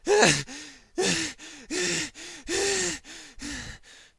panic_0.ogg